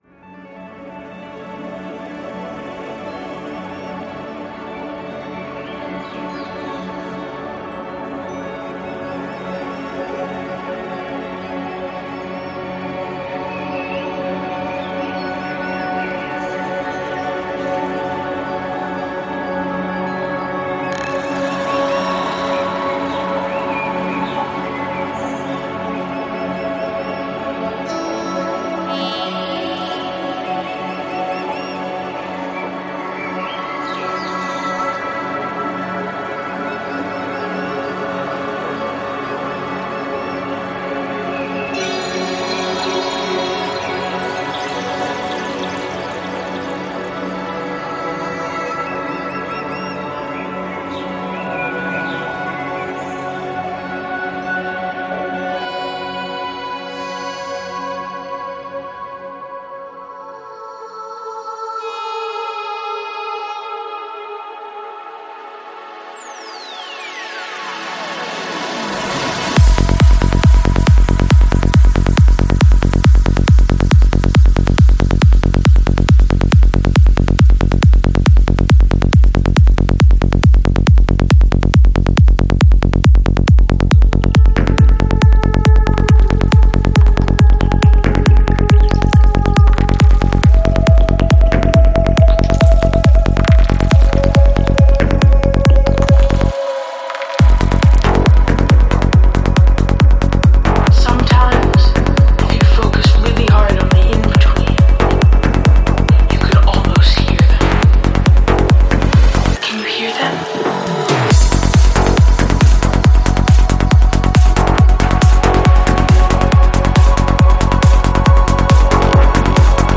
Жанр: Psy Trance